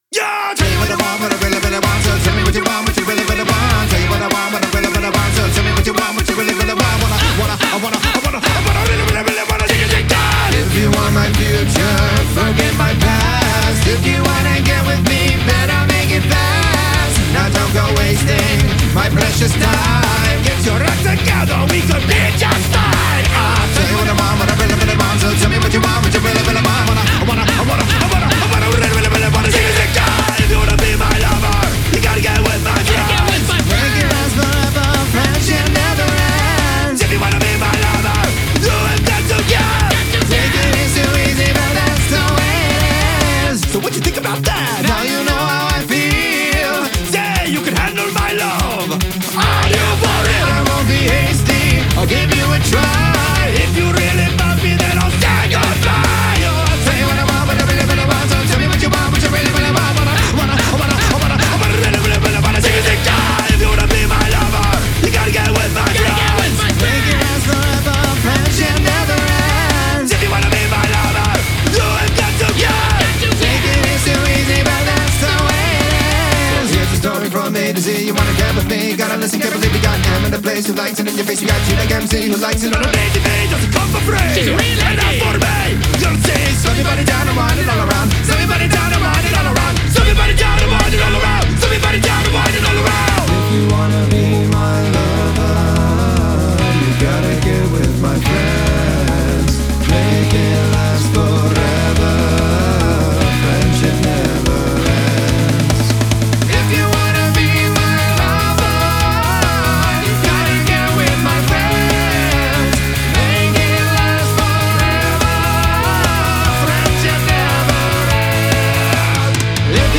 BPM145-145
Audio QualityPerfect (High Quality)
Pop Metal song for StepMania, ITGmania, Project Outfox
Full Length Song (not arcade length cut)